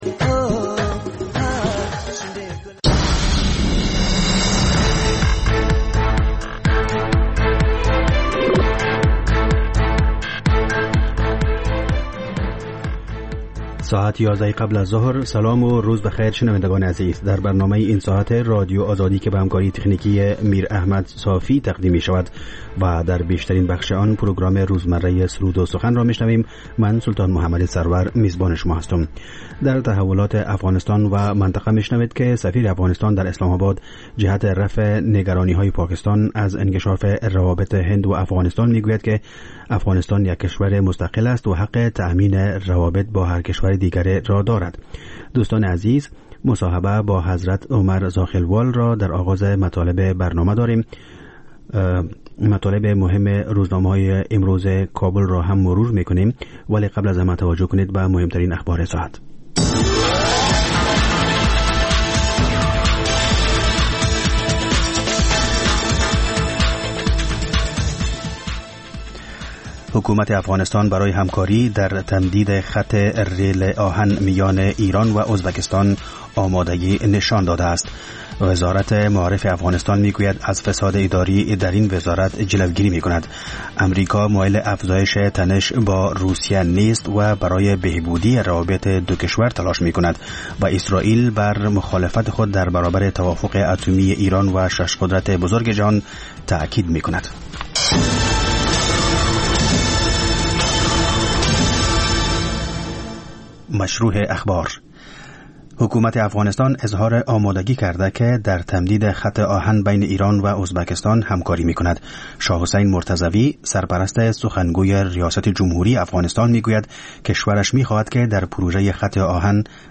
خبر ها و گزارش‌ها، سرود و سخن